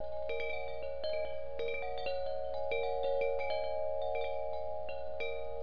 The 5 copper tubes with a solid wood ball clapper give this wind chime soft, mellow tones that are very soothing to listen to. Keep in mind that with their soft tones they will not have the same volume as some other wind chimes.